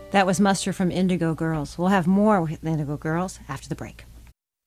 (captured from the webstream)